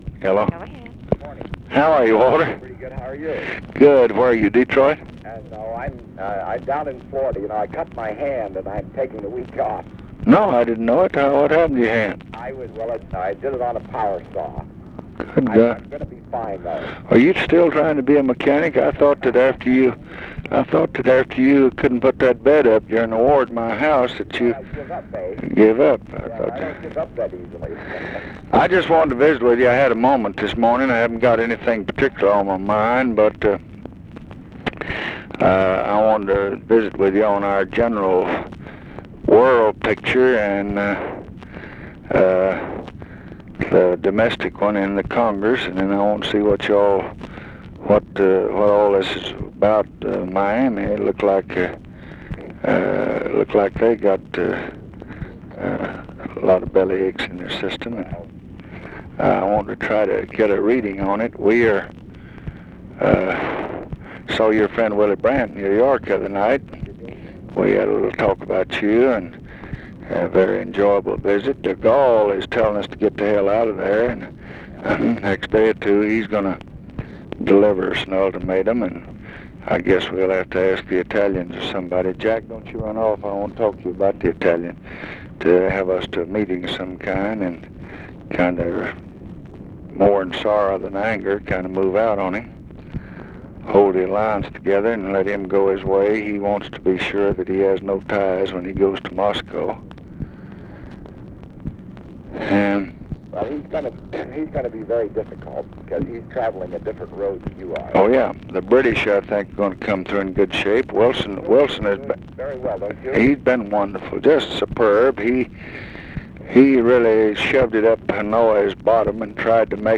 Conversation with WALTER REUTHER and OFFICE CONVERSATION, March 7, 1966
Secret White House Tapes